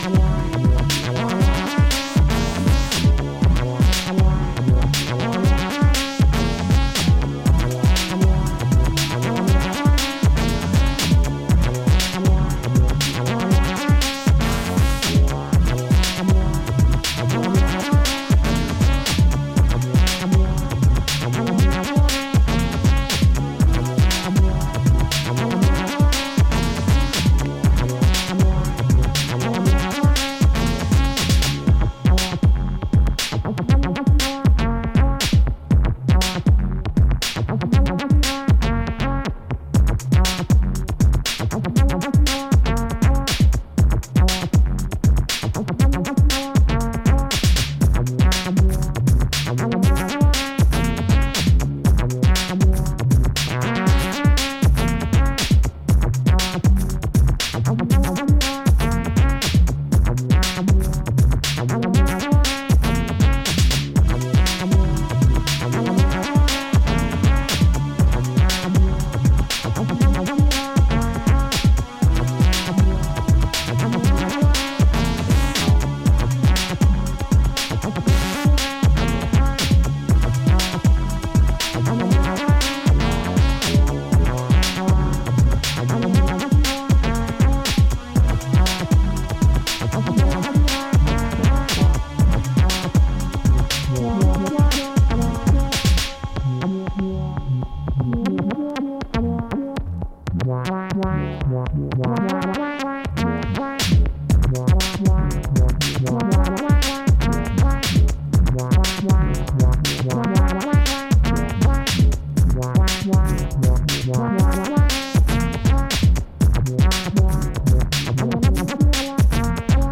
ヘヴィウェイトエレクトロ